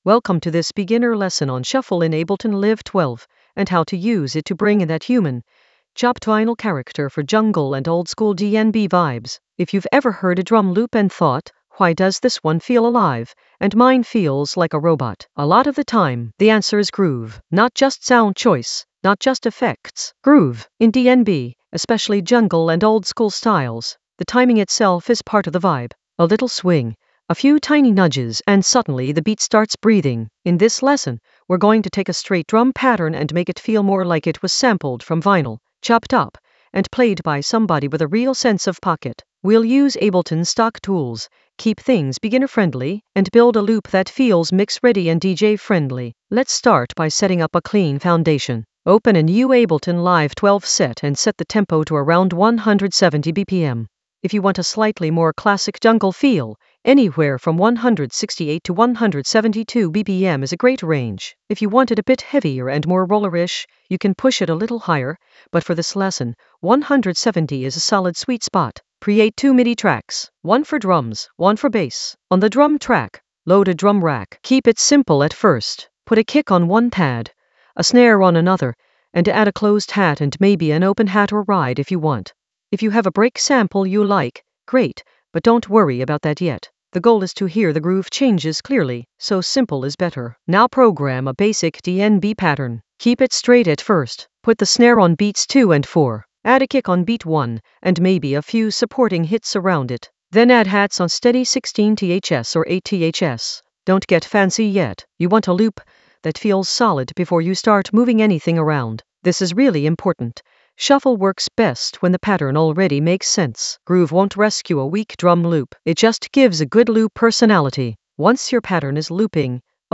An AI-generated beginner Ableton lesson focused on Shuffle in Ableton Live 12: humanize it with chopped-vinyl character for jungle oldskool DnB vibes in the DJ Tools area of drum and bass production.
Narrated lesson audio
The voice track includes the tutorial plus extra teacher commentary.